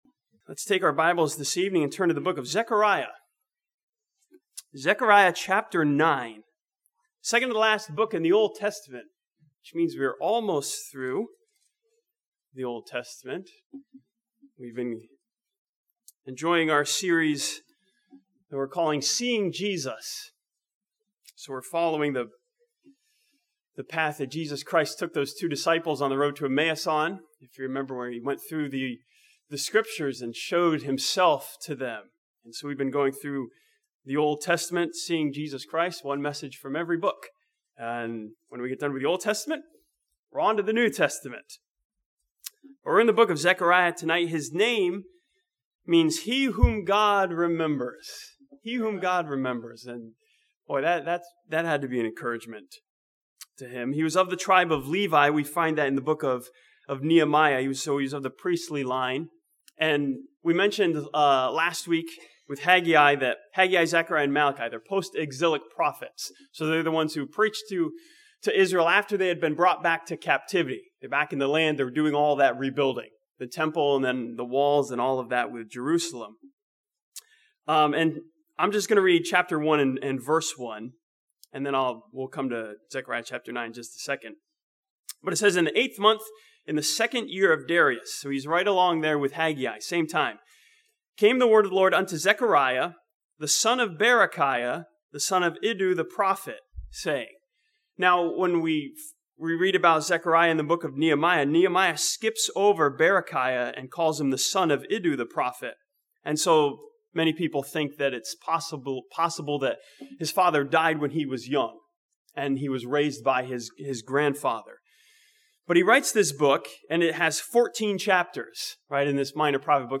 This sermon from Zechariah chapter 9 sees Jesus as the coming King who came once to die and is coming again to reign.